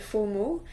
Captions English Add a one-line explanation of what this file represents French Pronciation de FOMO au Québec
FOMO_Québec.wav